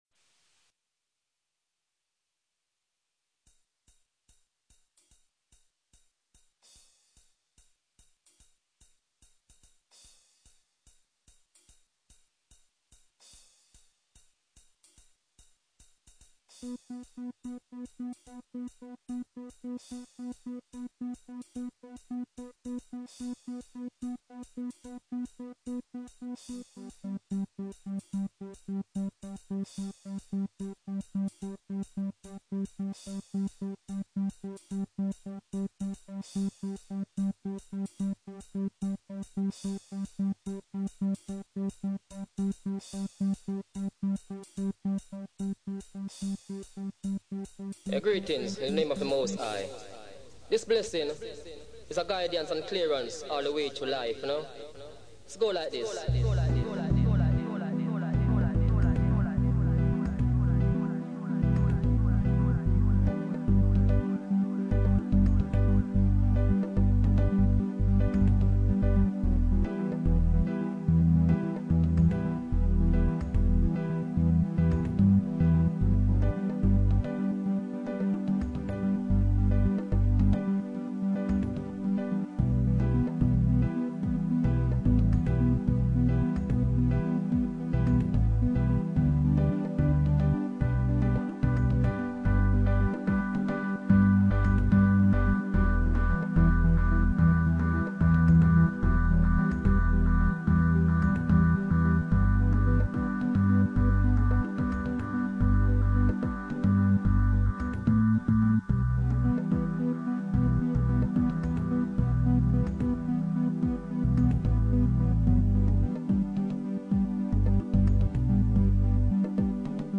The dubness wing takes it forward...